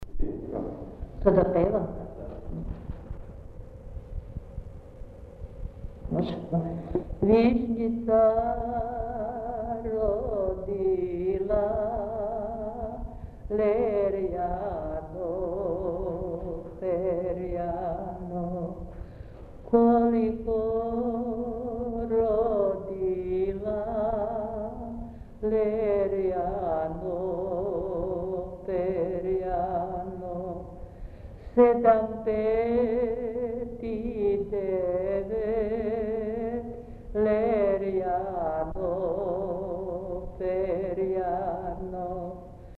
Mesto: Kalaz
Napomena: Ove dve melodije izvedene su kao jedna celina. Reč je o srpskim obrednim pesmama, u prošlosti u Kalazu pevane na (pravoslavni) Uskrs posle večernje službe uz kolo sa provlačenjem, igrano oko crkve.